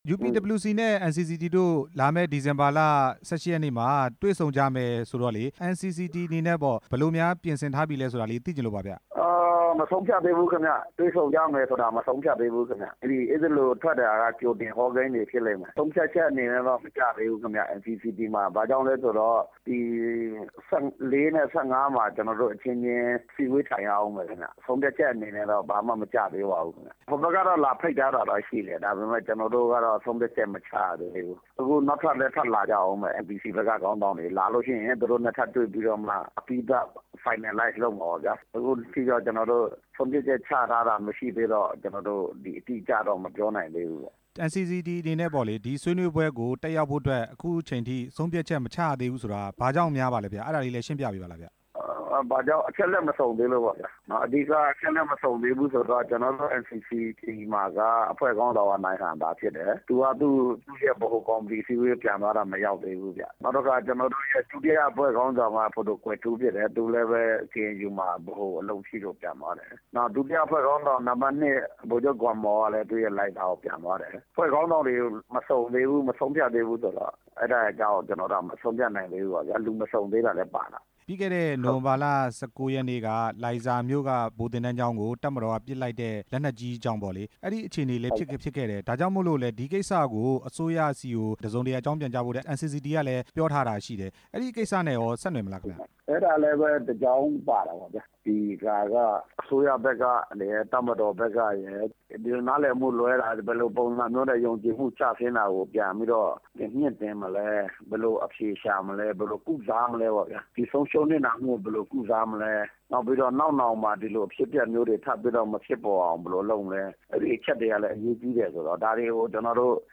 မေးမြန်းချက် အပြည့်အစုံ